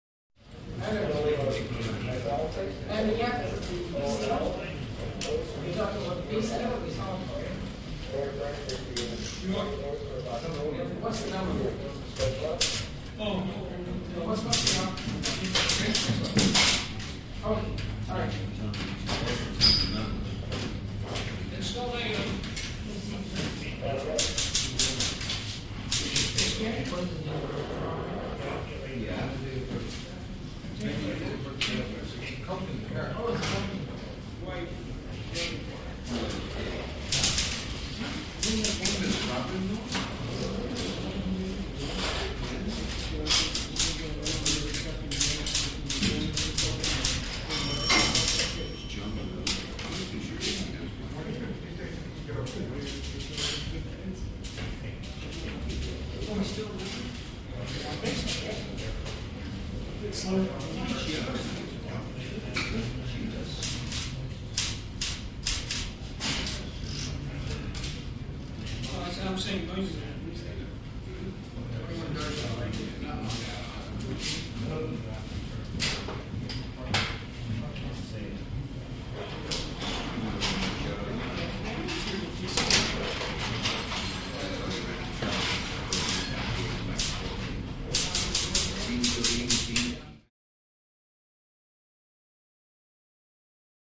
Police Booking Office